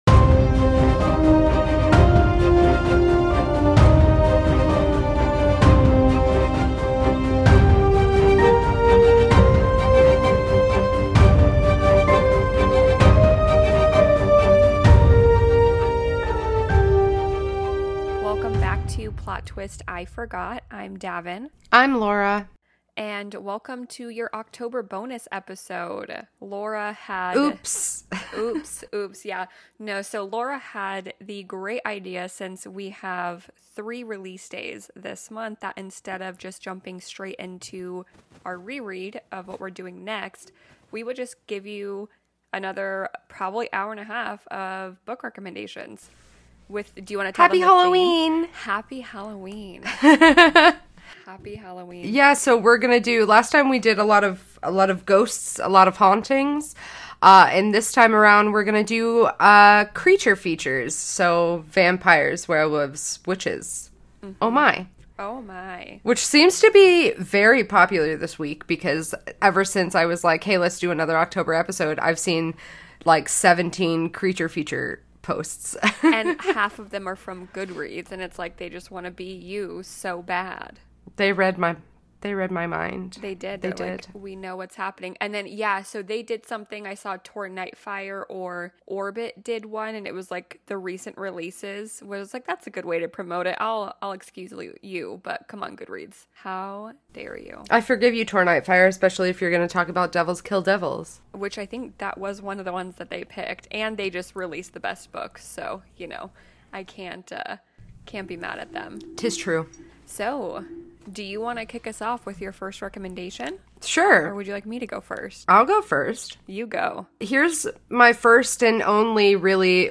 Sit down, buckle up, and forgive us for this audio.